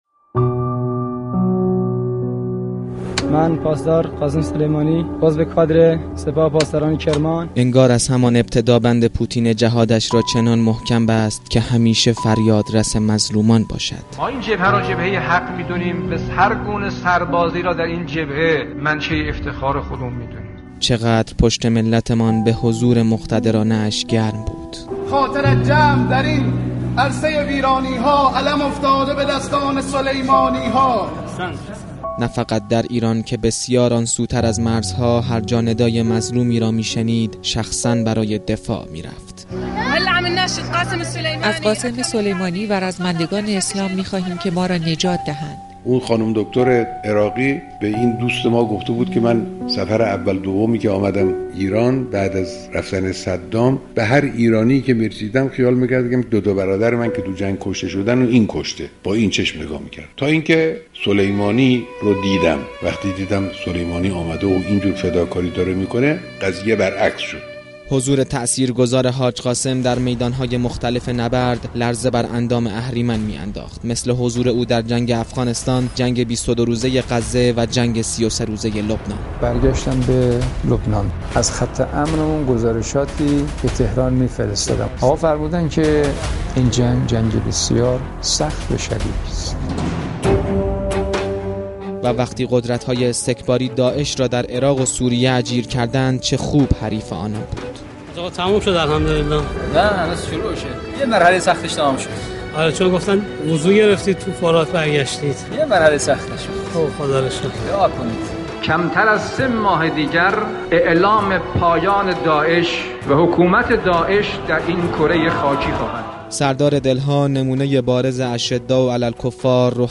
گزارش خبرنگار رادیو زیارت را بشنوید: